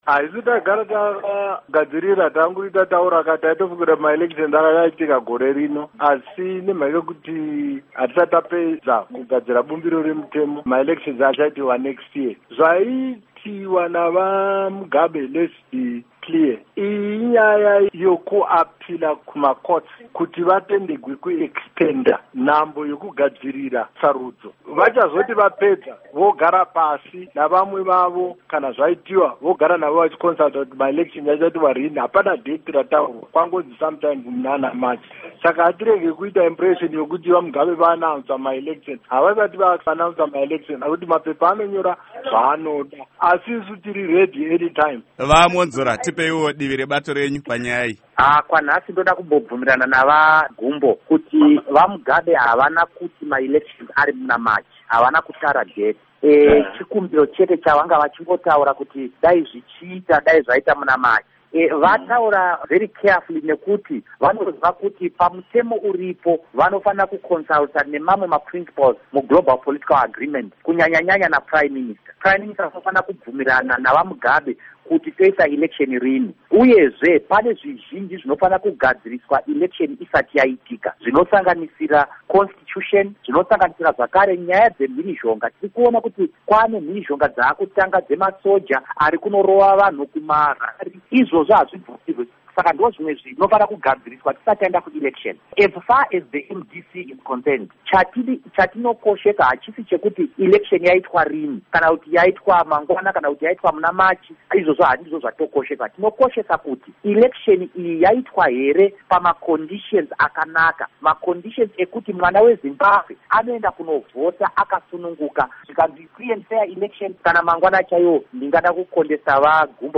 Hurukuro naVaRugare Gumbo Pamwe naVaDouglas Mwonzora